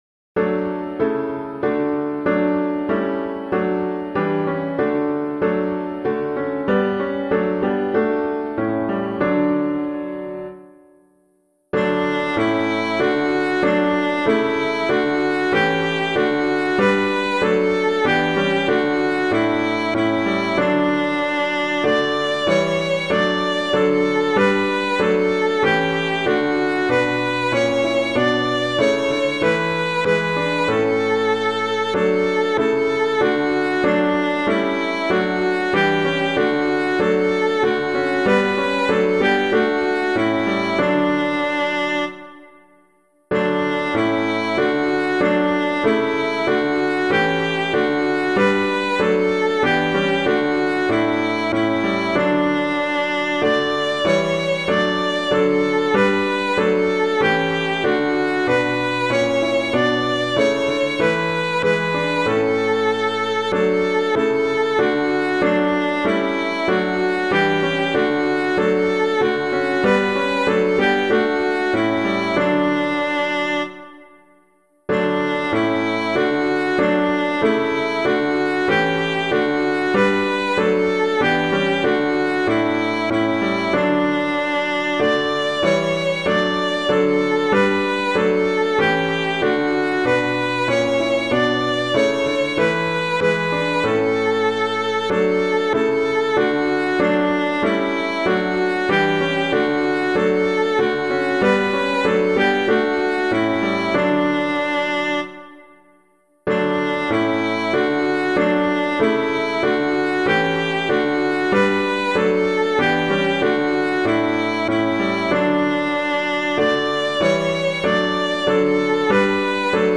Christ Is Made the Sure Foundation [Neale - TANTUM ERGO] - piano.mp3